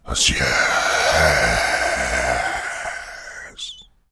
Zombie Voices Demo
zombie_Miscellaneous_4.wav